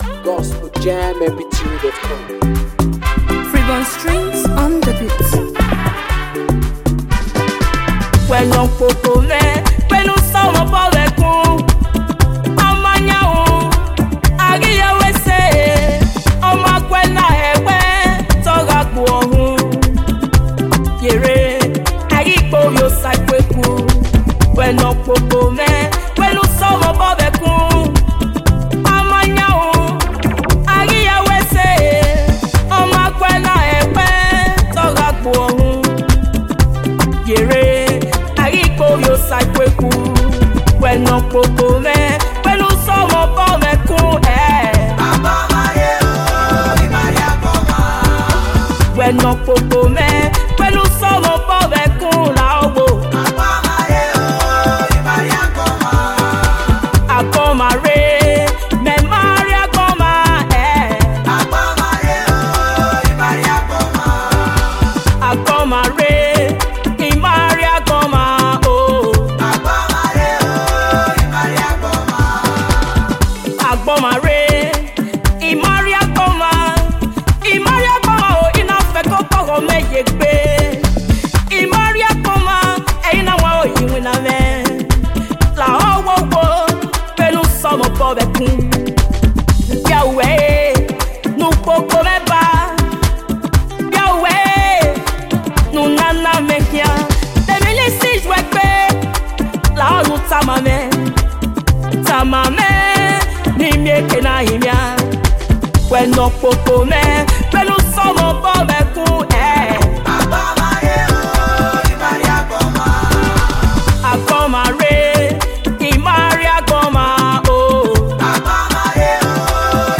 Gospel singer